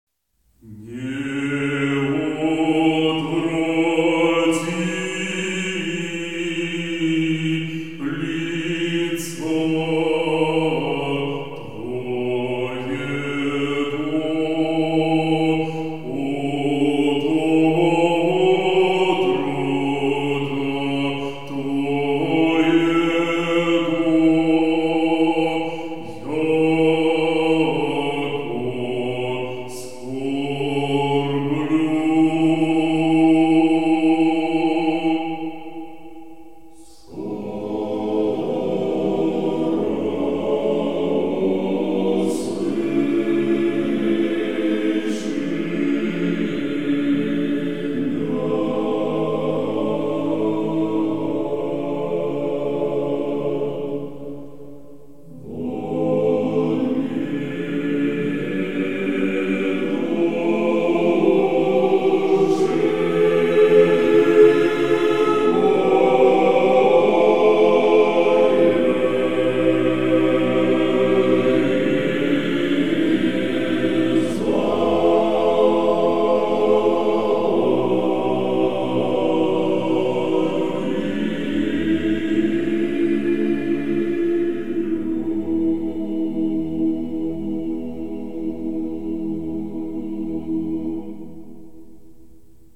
Аудиокнига Не отврати лица Твоего | Библиотека аудиокниг